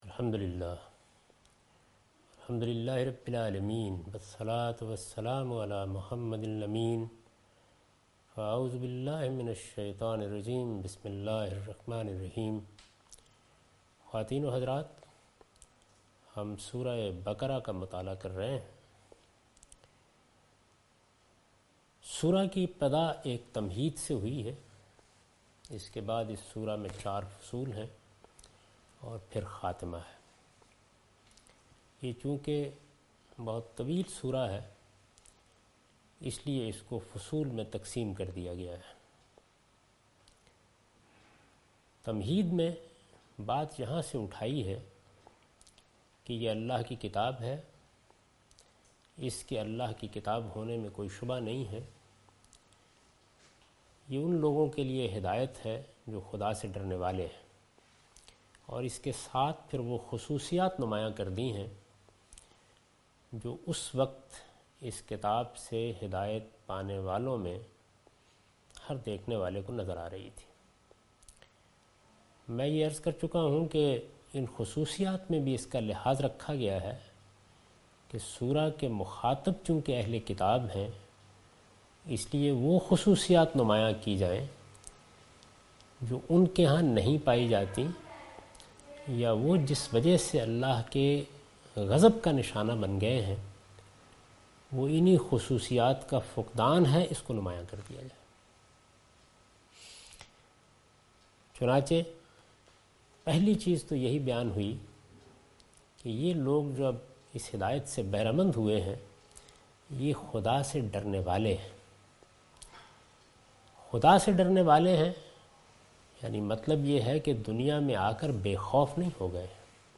Surah Al-Baqarah - A lecture of Tafseer-ul-Quran – Al-Bayan by Javed Ahmad Ghamidi. Commentary and explanation of verse 3 (Lecture recorded on 14th March 2013).